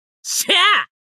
刀剑乱舞_Nansen-attack2.mp3